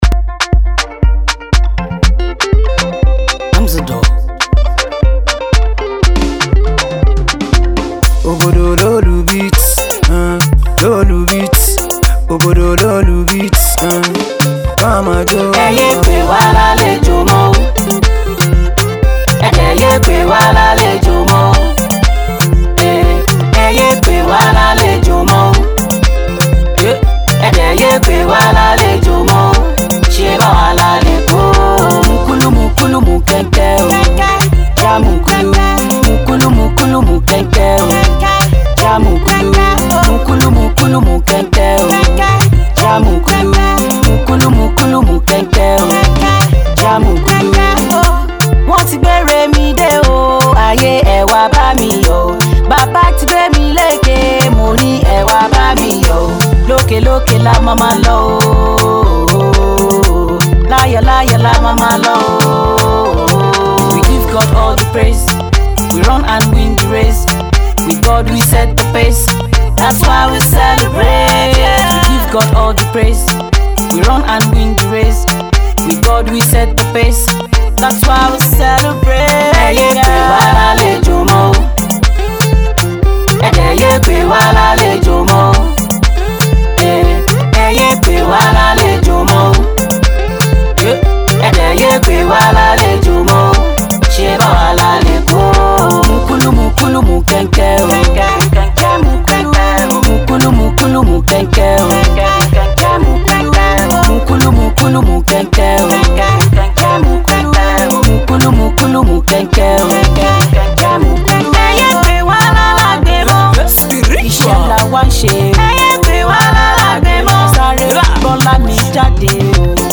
The song has a danceable tune that would grow on you!!!